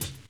Wu-RZA-Hat 12.WAV